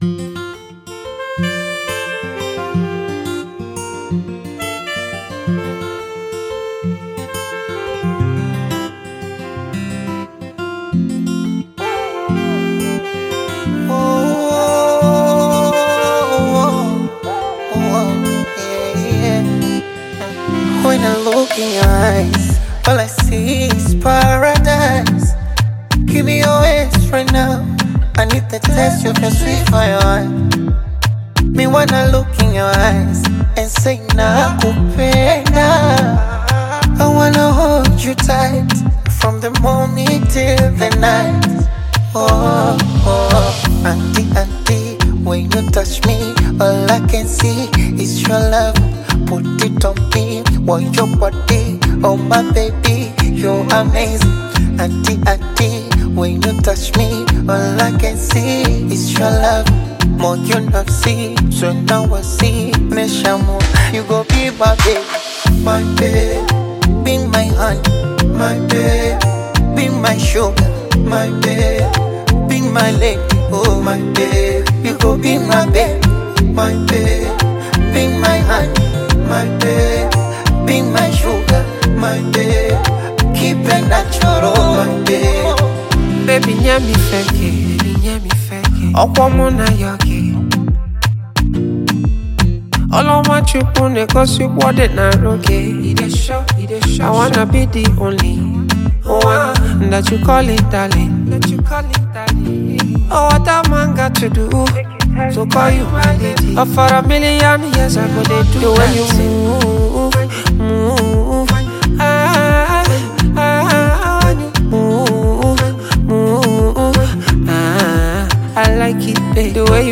a top-rated Tanzanian bongo Flava recording artist
love song